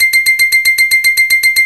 MUSIC BOX.wav